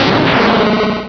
Cri de Tyranocif dans Pokémon Rubis et Saphir.